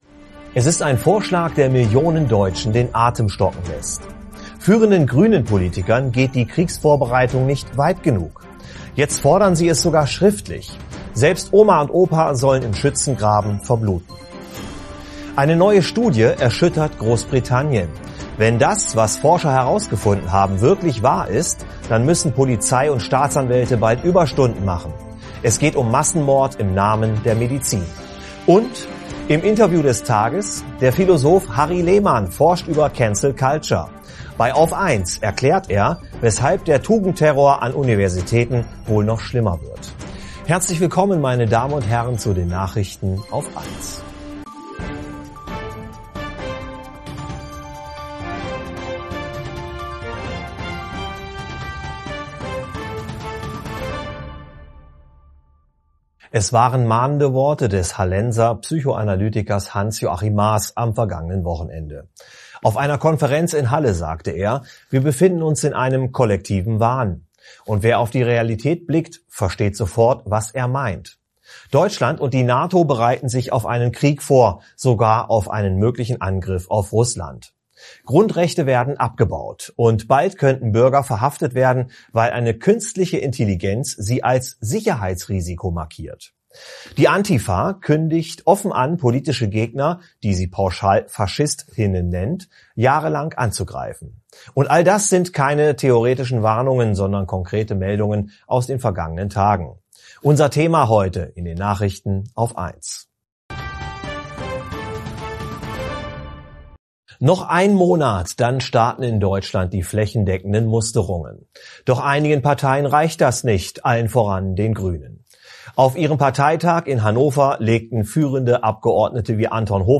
Im Interview des Tages